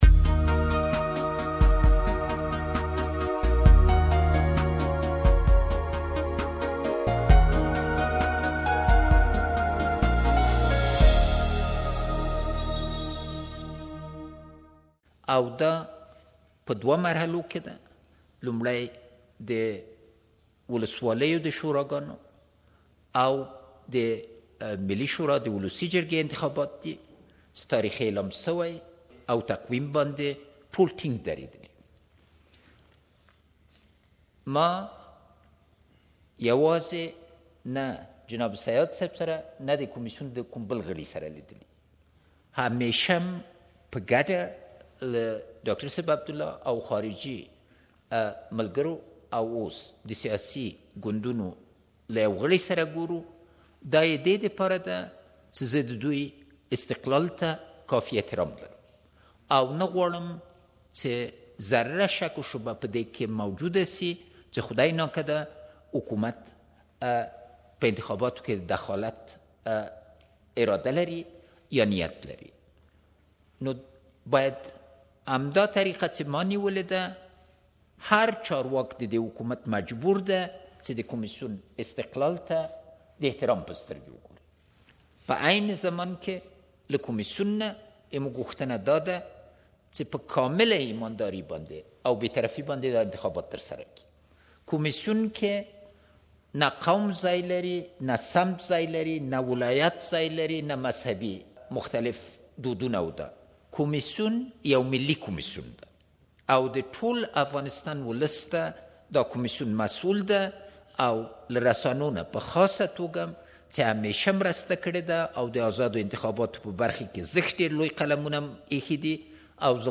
Please listen to President Ashraf Ghani’s speech here.